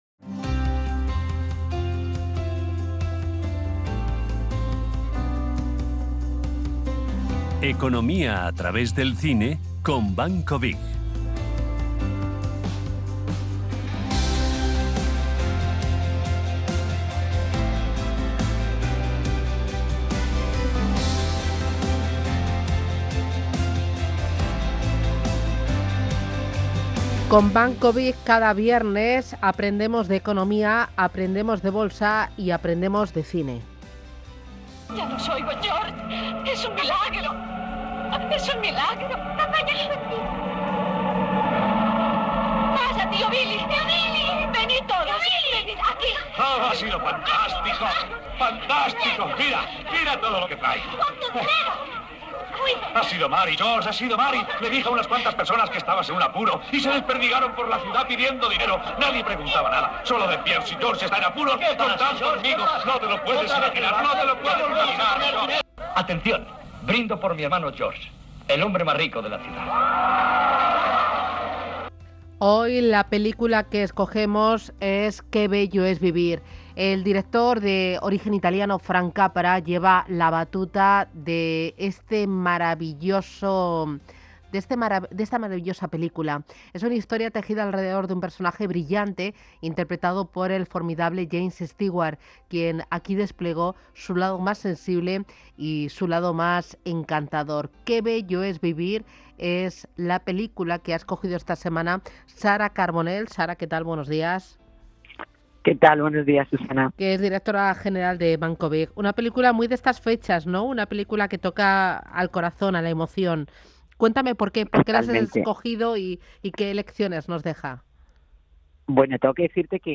Si quieres saber más, aquí tienes disponible el corte radiofónico de la sección.